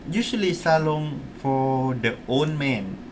S2 = Laos male
Intended Word: old Heard as: own
There is [n] rather than [l] at end of the word.